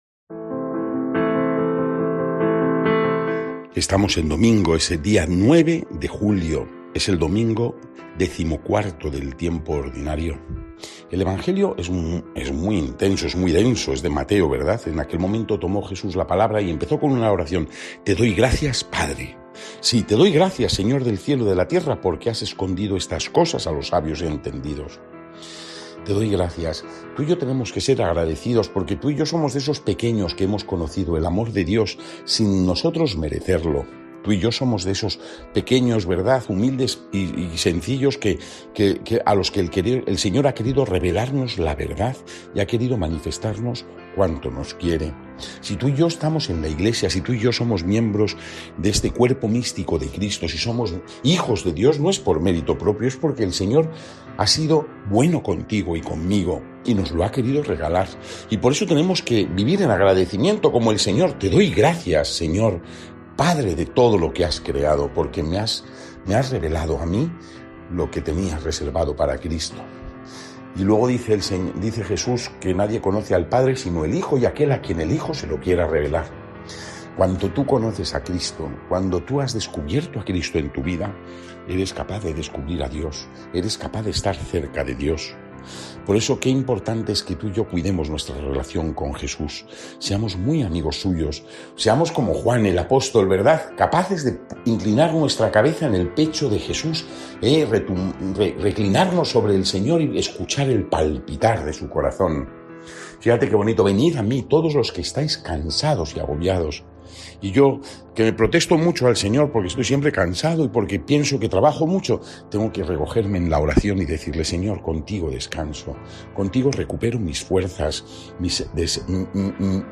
Lectura del santo evangelio según san Mateo 11, 25-30